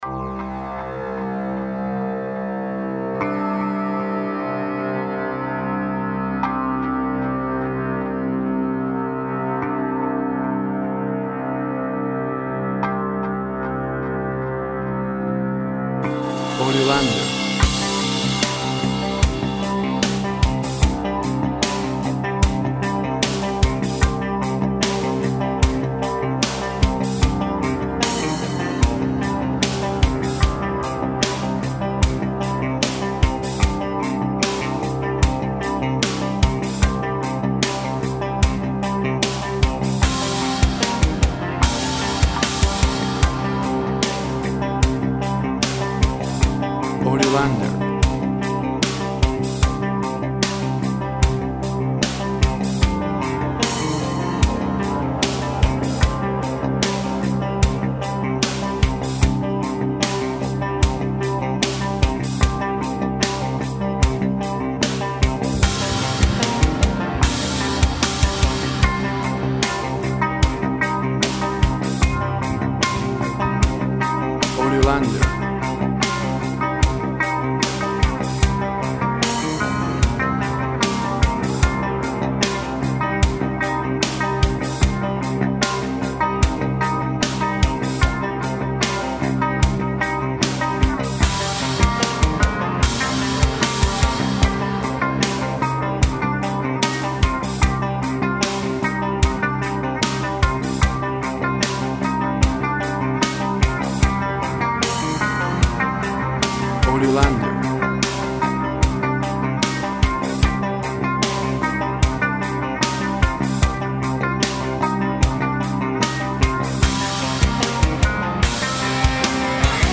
Tempo (BPM) 95